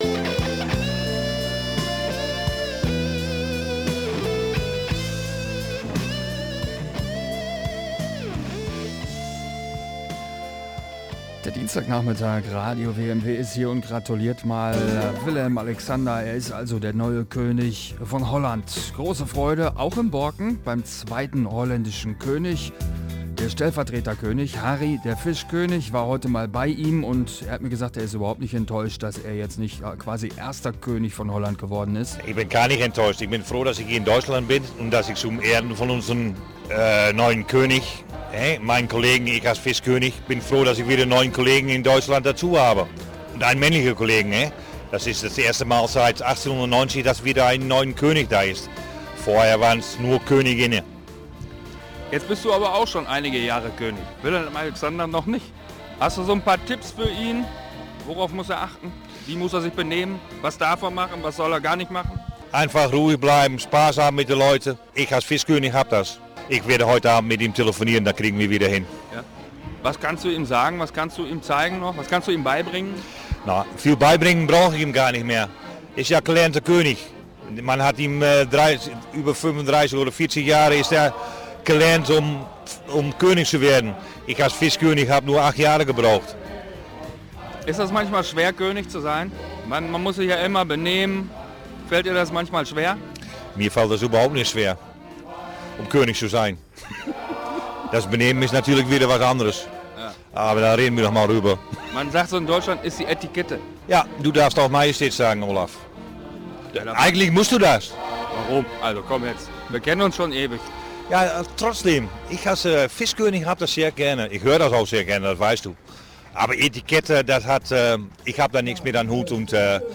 Interview bei WMW